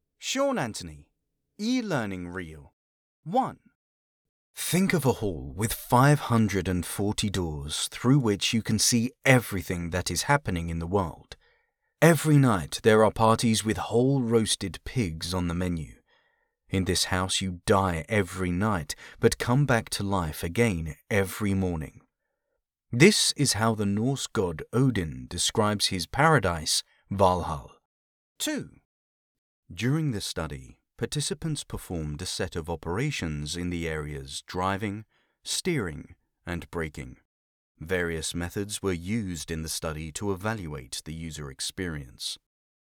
Comercial, Profundo, Natural, Llamativo, Travieso
E-learning